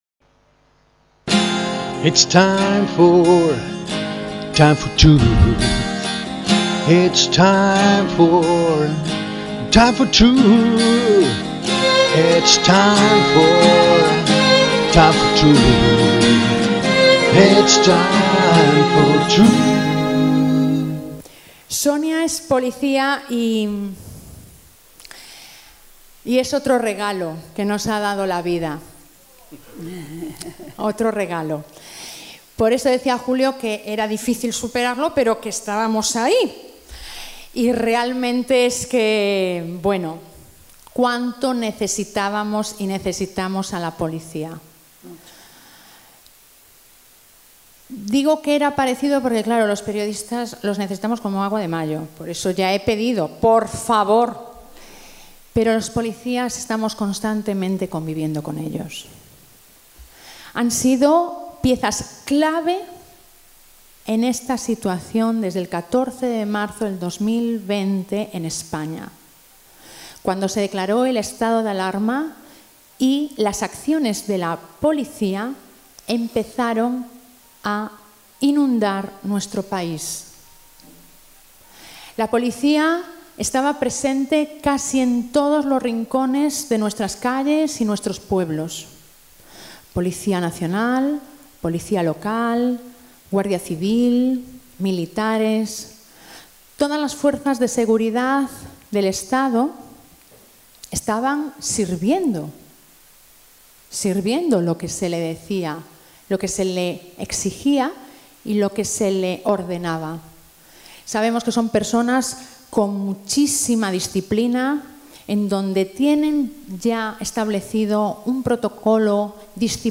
POLICÍAS POR LA LIBERTAD. Rueda de prensa de UNIDOS por la VERDAD y la VIDA EN LIBERTAD